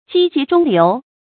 擊楫中流 注音： ㄐㄧ ㄐㄧˊ ㄓㄨㄙ ㄌㄧㄨˊ 讀音讀法： 意思解釋： 比喻立志奮發圖強。